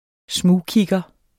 Udtale [ ˈsmuː- ]